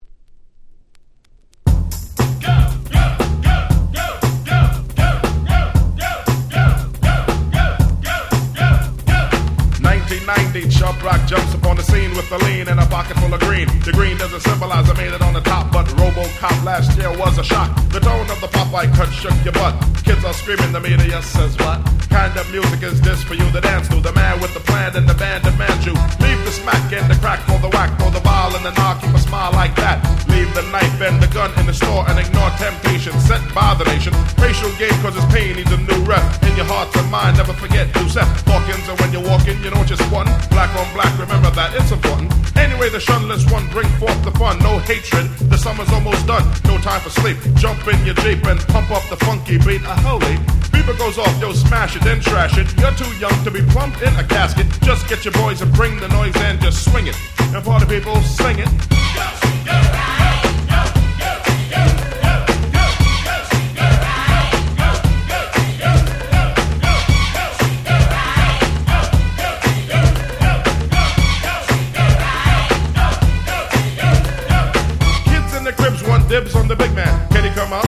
DanceのShow Caseなんかに使われる事も非常に多いBPM速めの超踊れる1曲！！
チャブロック 90's Boom Bap ブーンバップ